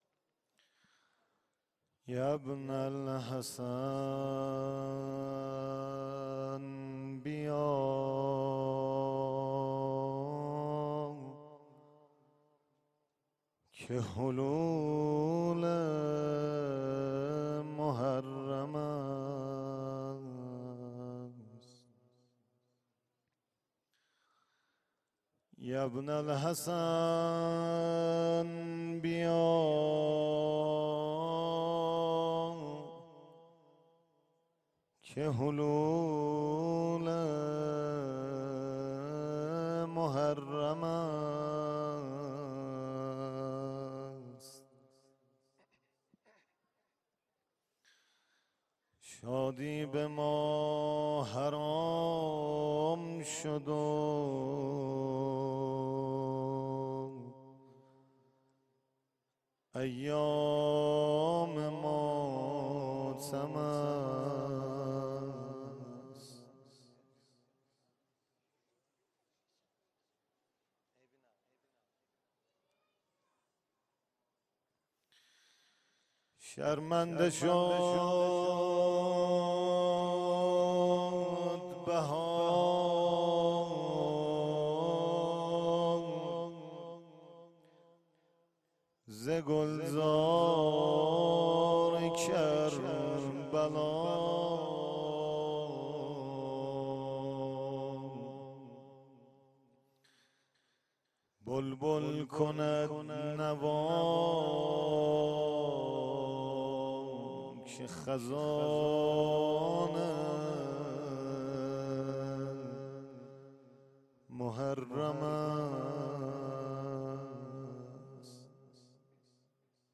مسجد جامع مهدی (عج)
مدح
شب اول محرم 1398